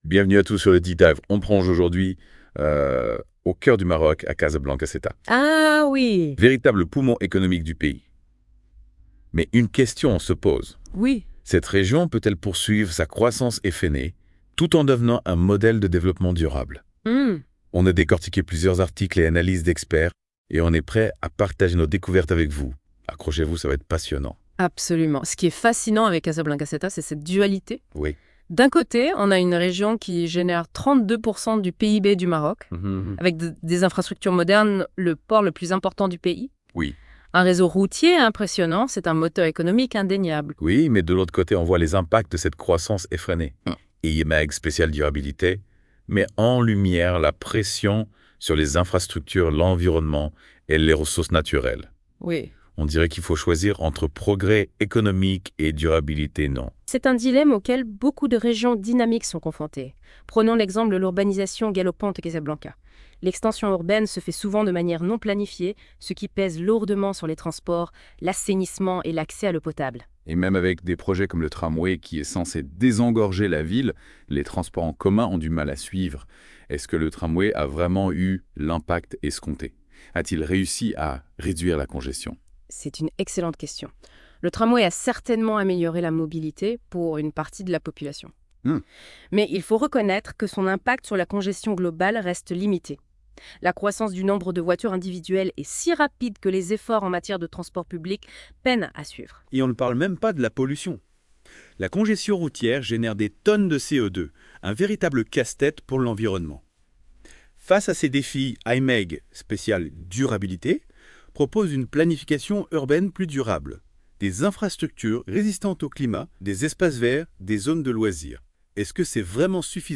Les chroniqueurs de la Web Radio R212 ont lus attentivement ce I-MAG Spécial Durabilité de L'ODJ Média et ils en ont débattu dans ce podcast